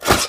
STEPS Wood, Reverb, Run 40, Large Scratch.wav